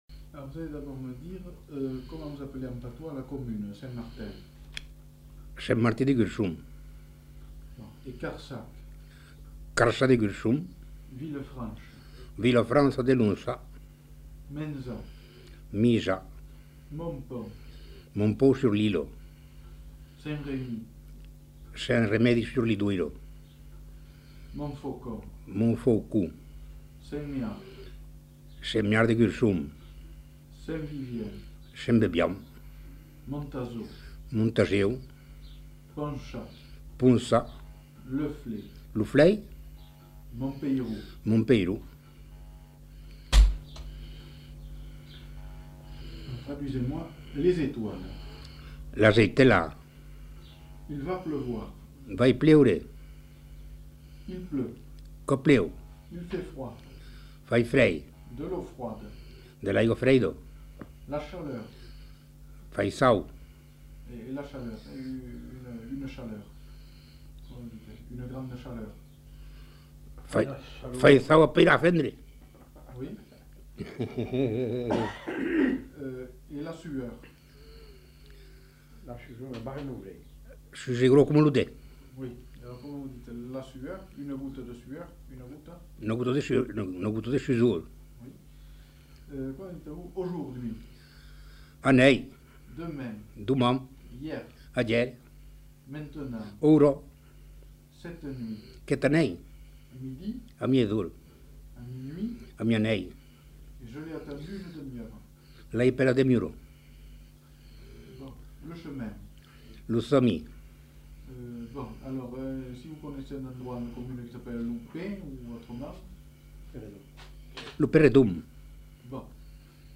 Traduction de mots et de phrases en occitan (dialecte local)
Lieu : Saint-Martin-de-Gurson
Genre : parole